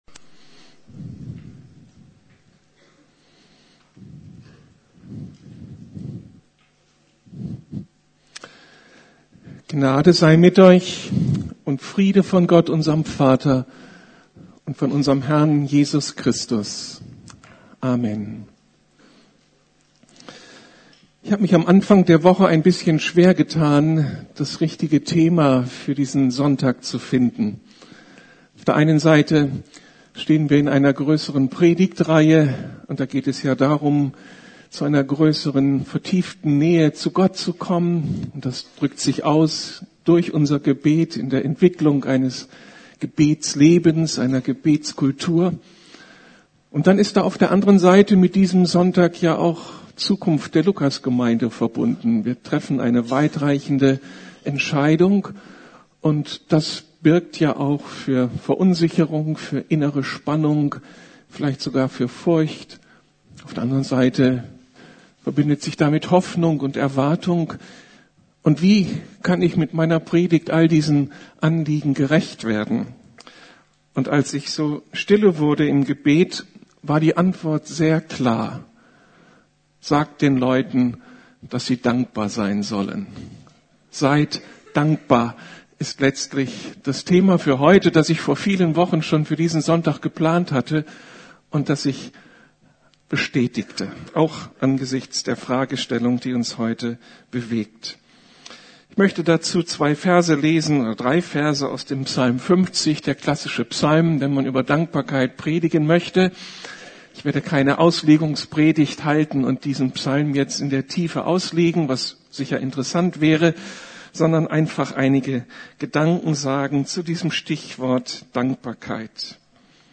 Dankbarkeit - ein Schlüssel zum Segen Gottes ~ Predigten der LUKAS GEMEINDE Podcast